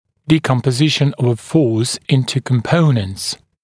[ˌdiːkɔmpə’zɪʃn əv ə fɔːs ‘ɪntə kəm’pəunənts][ˌди:компэ’зишн ов э фо:с ‘интэ кэм’поунэнтс]разложение силы на компоненты